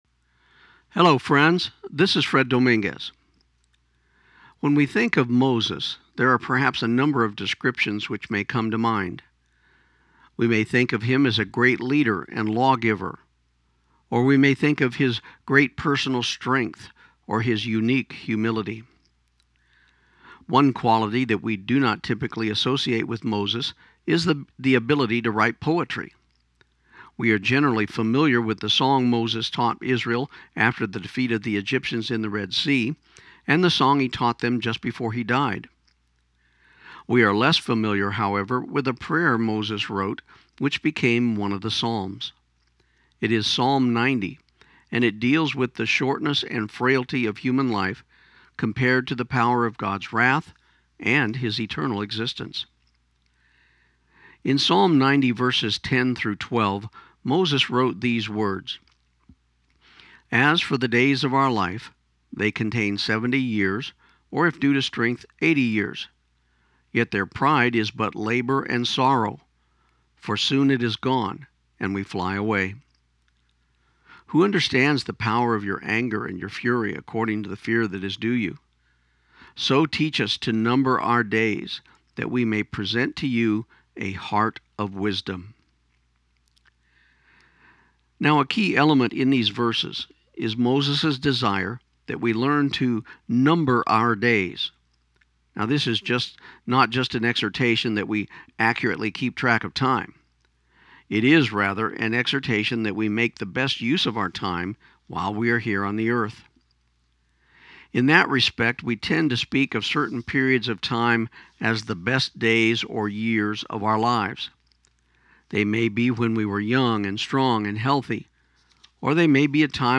This program aired on KIUN 1400 AM in Pecos, TX on June 29, 2016